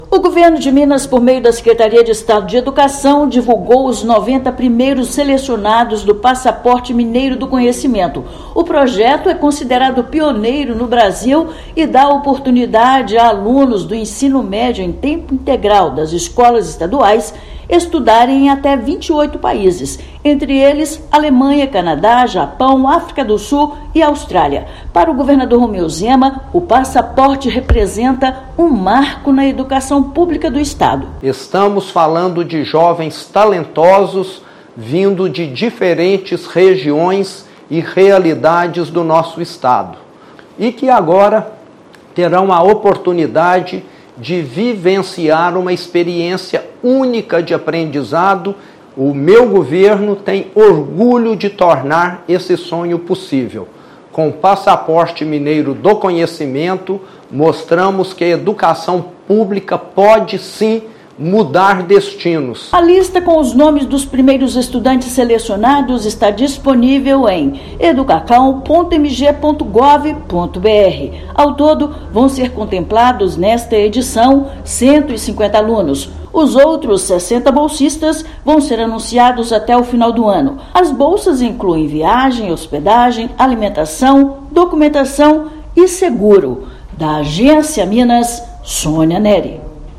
Segunda edição do Passaporte Mineiro do Conhecimento vai contemplar ao todo 150 jovens da rede pública com todas as despesas custeadas pelo Estado. Ouça matéria de rádio.